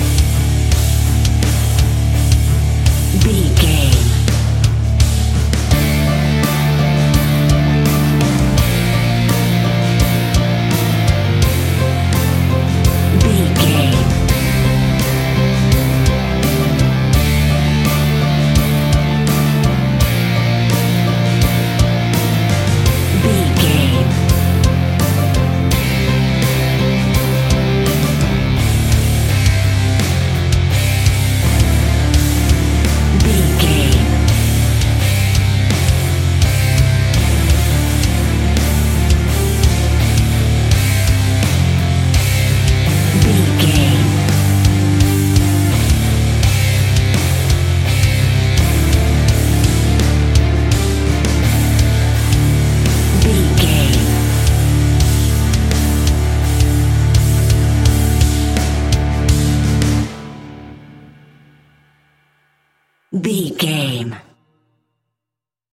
Epic / Action
Fast paced
Aeolian/Minor
heavy metal
scary rock
Heavy Metal Guitars
Metal Drums
Heavy Bass Guitars